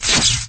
shock_impact.wav